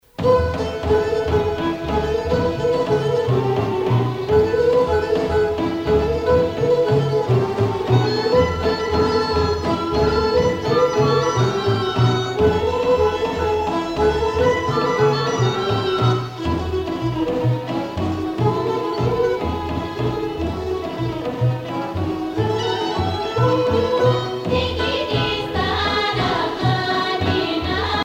Pièce musicale orchestrée n°15 avec choeur
Localisation Bulgarie